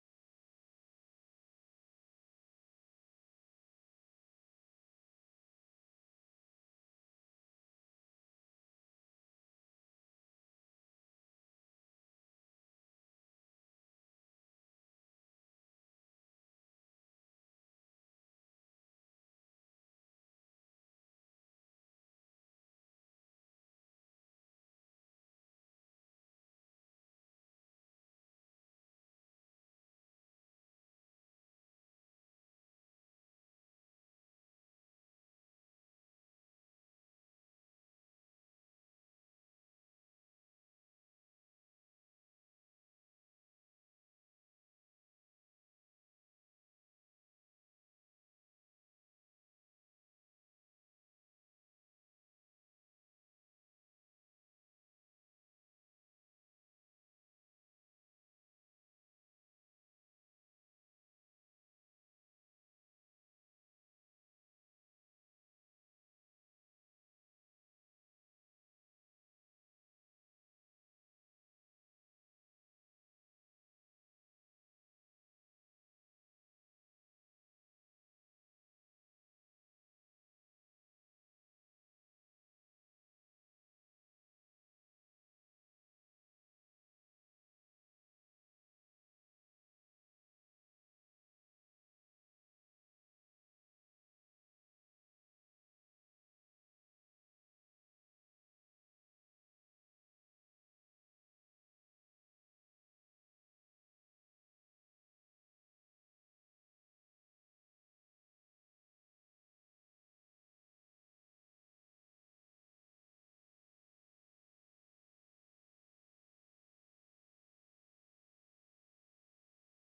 Scripture: Ruth 1:1-22 Series: Sunday Sermon